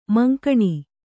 ♪ mankaṇi